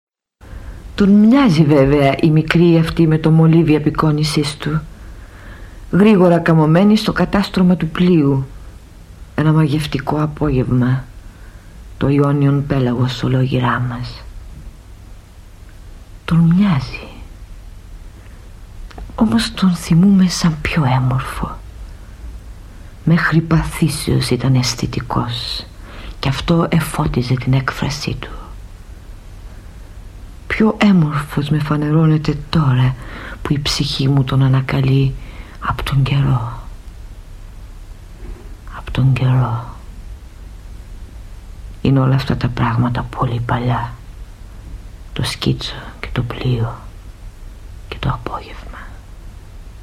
�������� - Cavafy Reading